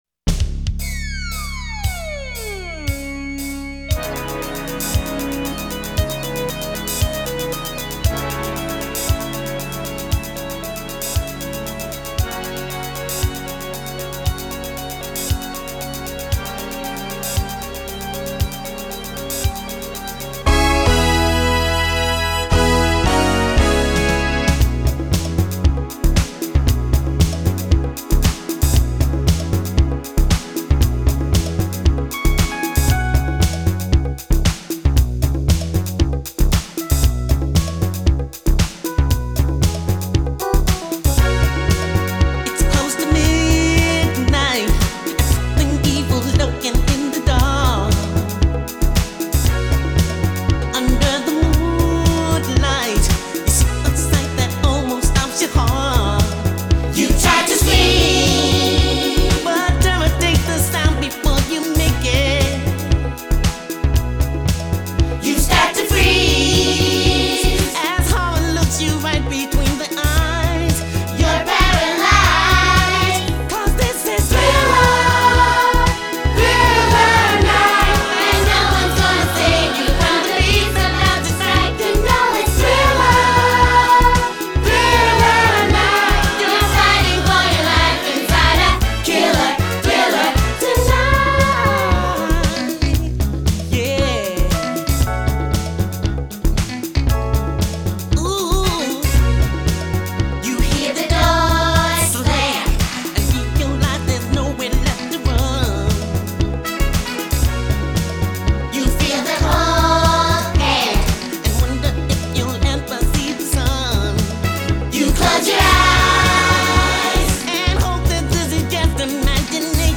We have some more kids singing on this one.